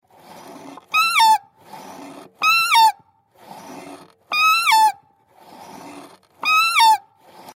Cat
audio-cat.mp3